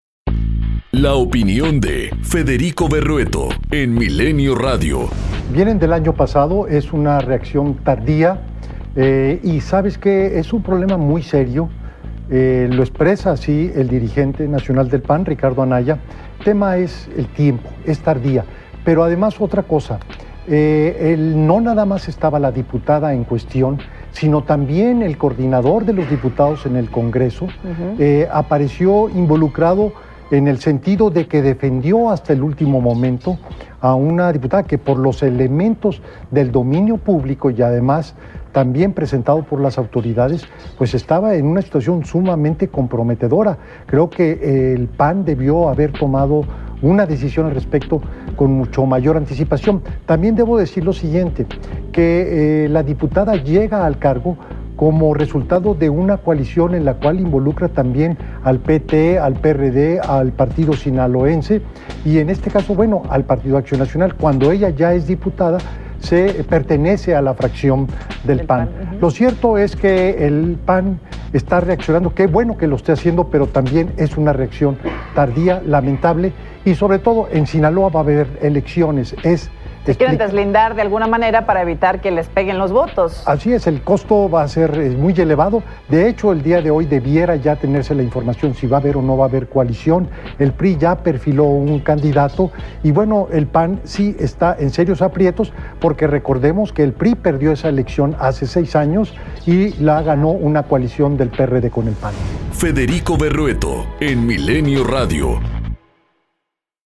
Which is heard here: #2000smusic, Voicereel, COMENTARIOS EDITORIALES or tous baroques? COMENTARIOS EDITORIALES